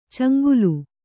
♪ caŋgulu